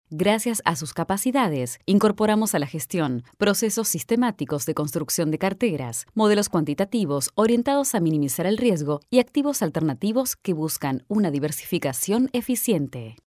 The studio features expert acoustic design and the latest in digital audio technology like : Microphones:  Kahayan 4k7 . Neumann TLM 170. Tube Tech equalizer, Apogee Symphony...  Quality and the better sound.
locutora neutra, latin american voice over, american voice talent